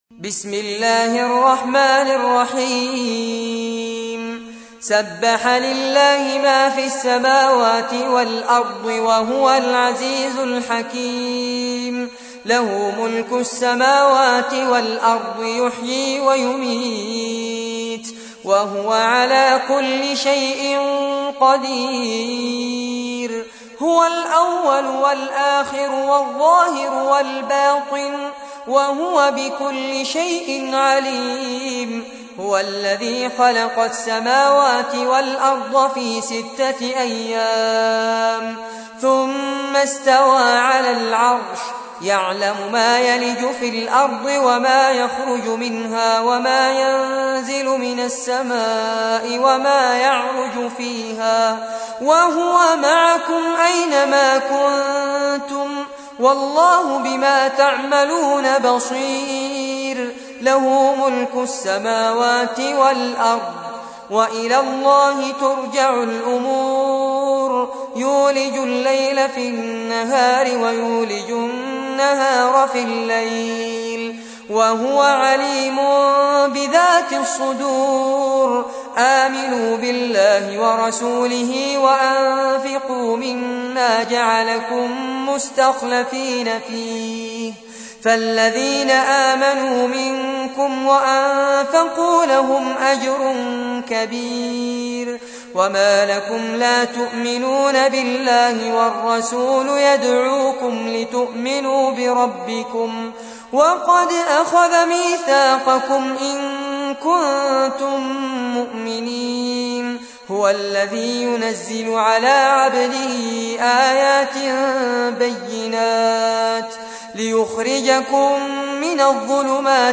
تحميل : 57. سورة الحديد / القارئ فارس عباد / القرآن الكريم / موقع يا حسين